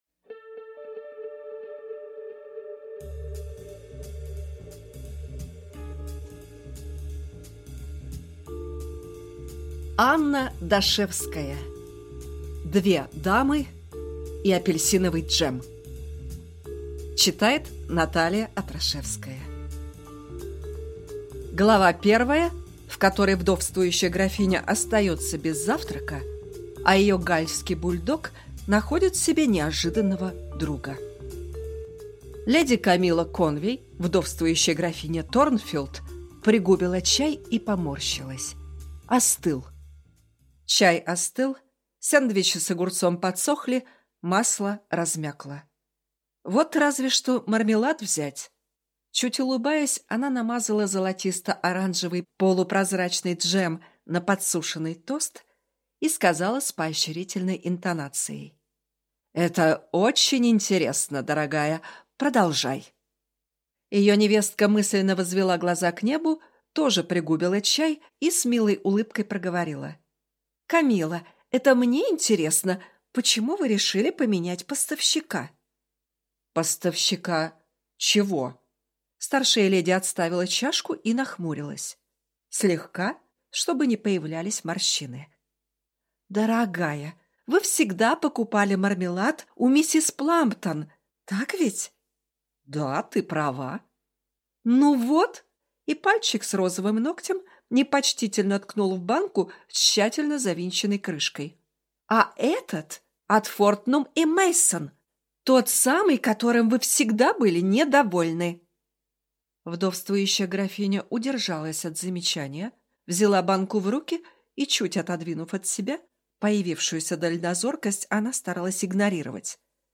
Аудиокнига Две дамы и апельсиновый джем | Библиотека аудиокниг